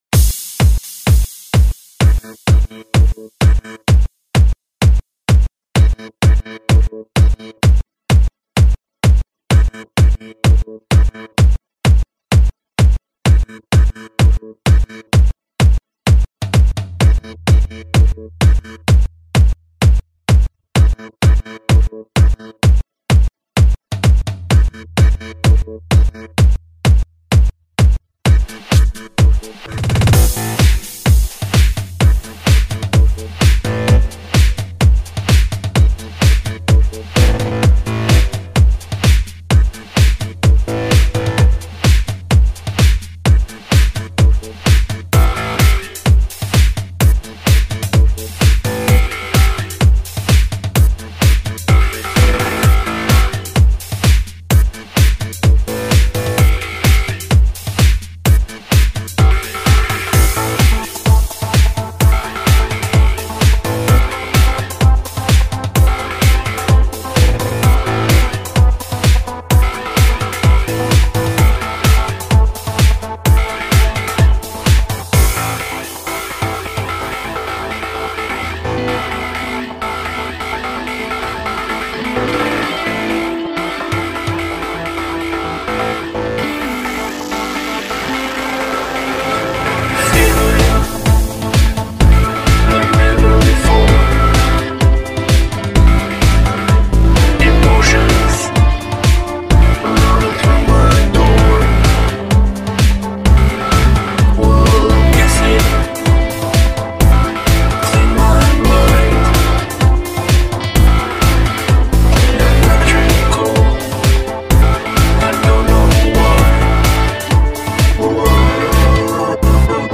música electrónica
remezclas y mashups